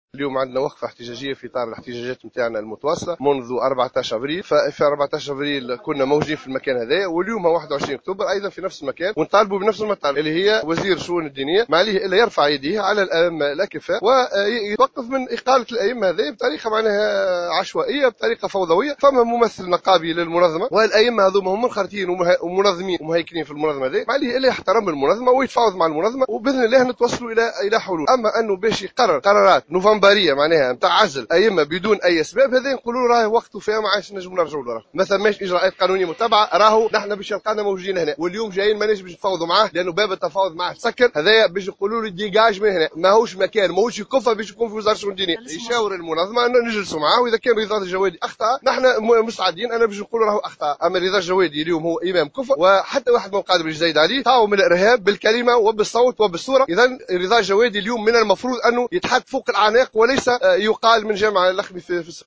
نفذت المنظمة التونسية للشغل اليوم الأربعاء 21 أكتوبر 2015 وقفة احتجاجية أمام مقر وزارة الشؤون الدينية للمطالبة برحيل الوزير عثمان بطيخ وذلك على خلفية قرارات عزل عدد من الأئمة التي اتخذها مؤخرا.